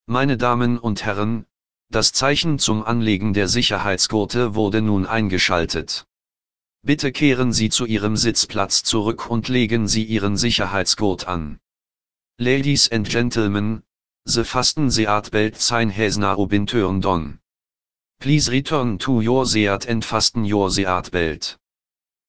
FastenSeatbelt.ogg